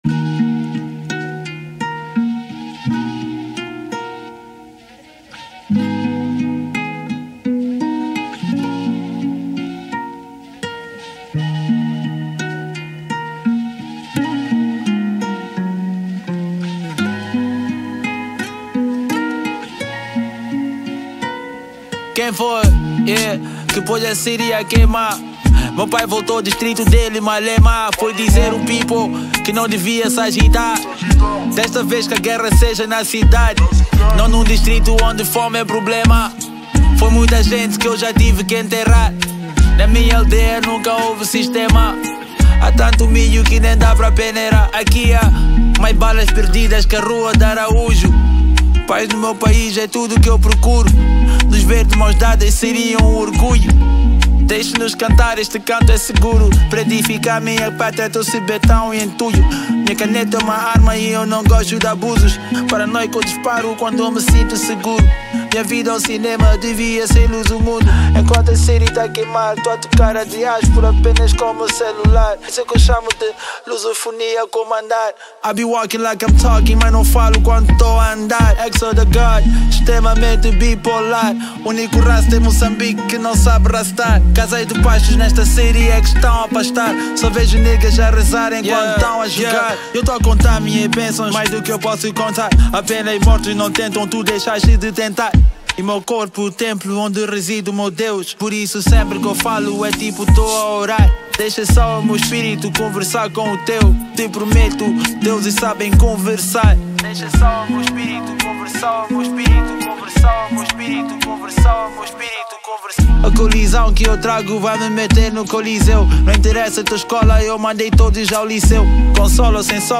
Genero: Hip-Hop/Rap